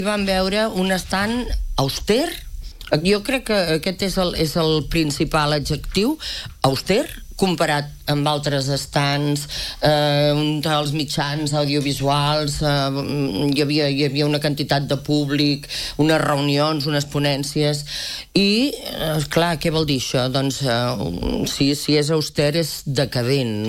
Ho ha explicat aquest dimecres en una entrevista al programa matinal de RCT, després de la visita del Gremi a la fira turística de Madrid.